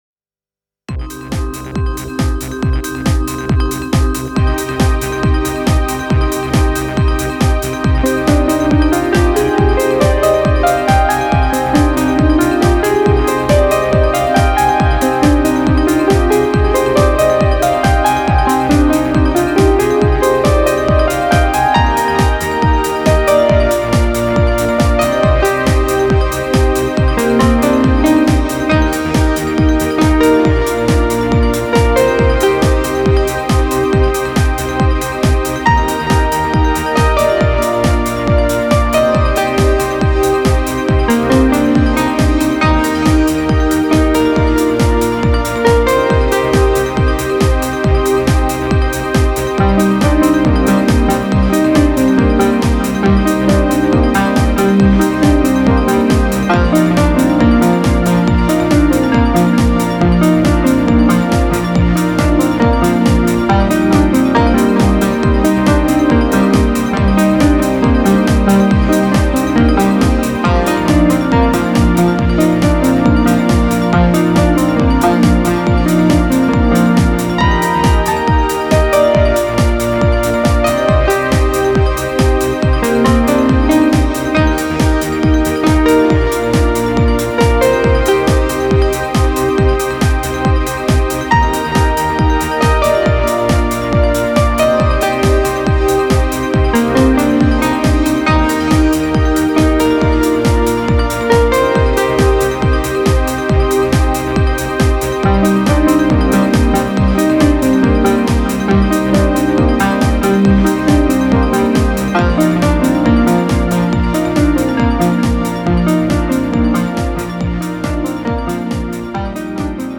Vikkelä musiikki
Kosketinsoitinpimputtelut. Ensin presetin ohjelmointia, sitten jammailua ja lopuksi recci päälle ja purkitus.